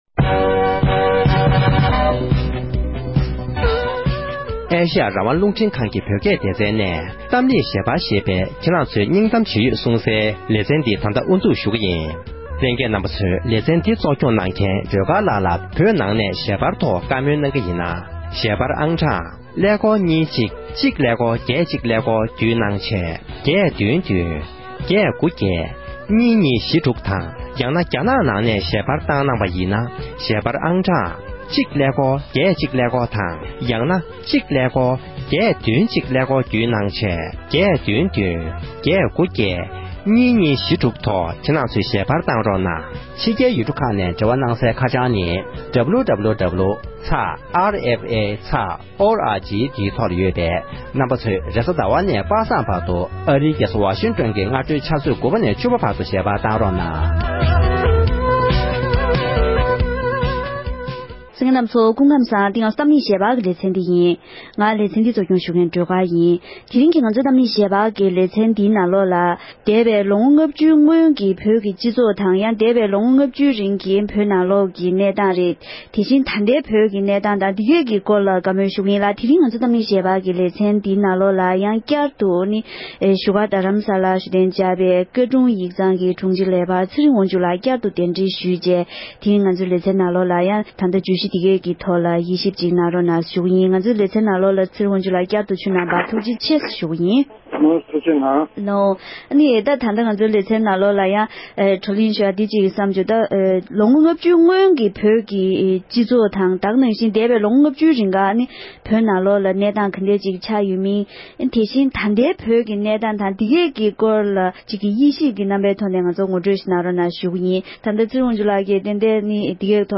༄༅༎དེ་རིང་གི་གཏམ་གླེང་ཞལ་པར་གྱི་ལེ་ཚན་ནང་དུ་འདས་པའི་ལོ་ངོ་ལྔ་བཅུའི་སྔོན་གྱི་བོད་ཀྱི་སྤྱི་ཚོགས་དང་ལམ་ལུགས་དེ་བཞིན་ལོ་ངོ་ལྔ་བཅུའི་རྗེ་ཀྱི་ད་ལྟའི་བོད་ཀྱི་སྤྱི་ཚོགས་དང་ལམ་ལུགས་ལ་དཔྱད་ཞིབ་ཞུ་བའི་ལེ་ཚན་གྱི་དུམ་མཚམས་དང་པོར་གསན་རོགས་ཞུ༎